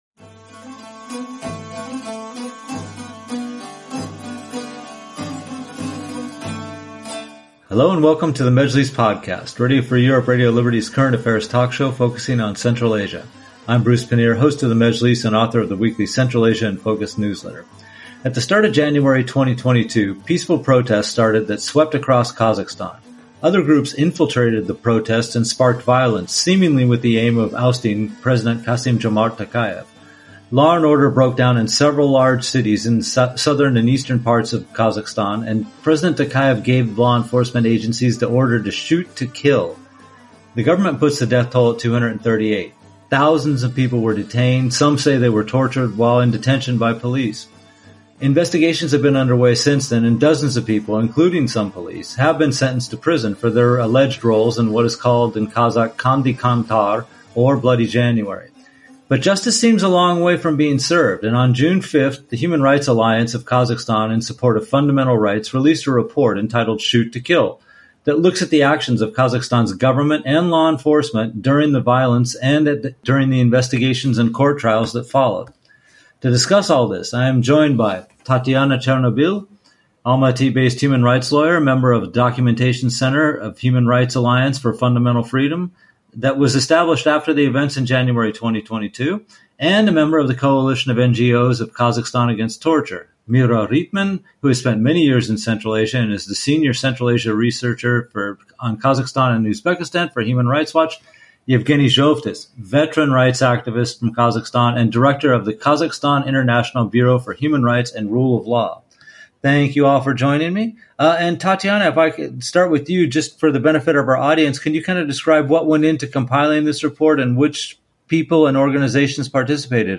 guests discuss the findings of a recent report on the events of "Bloody January" last year, in which at least 238 people were killed in a crackdown on nationwide protests in Kazakhstan.